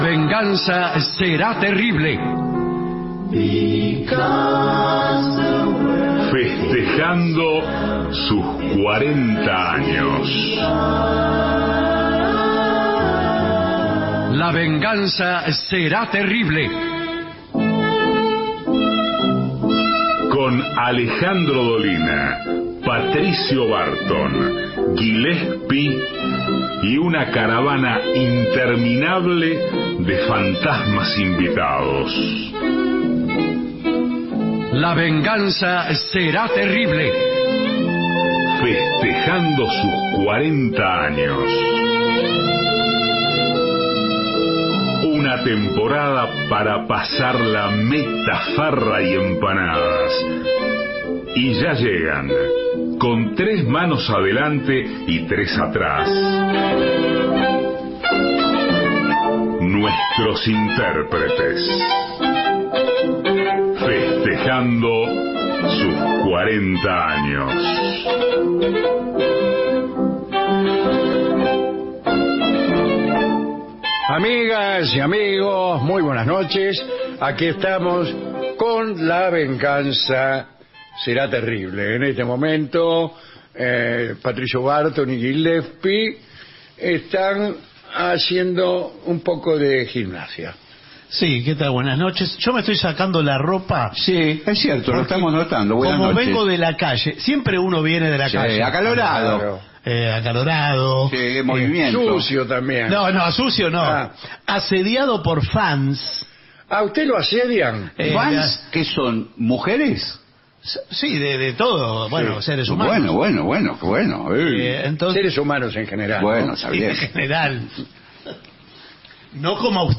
todo el año festejando los 40 años Estudios AM 750 Alejandro Dolina